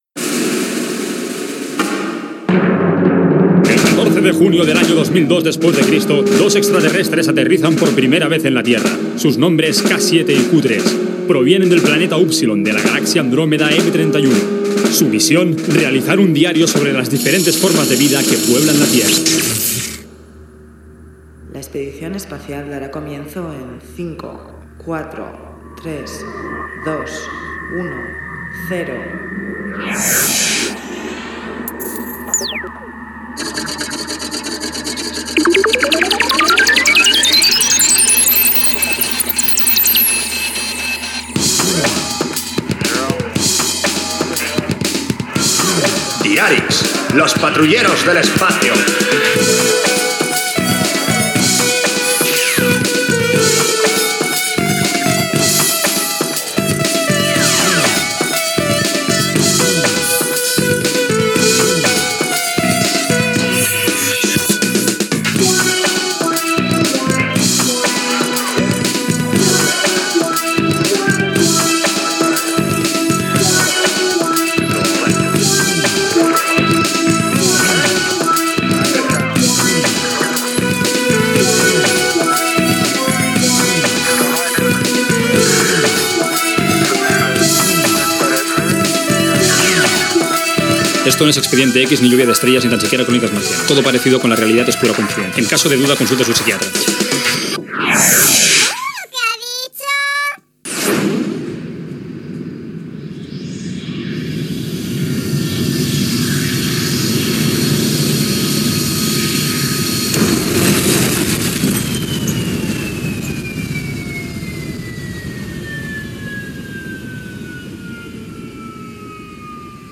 Careta del programa i l'arribada a la terra de dos extraterrestres
Ficció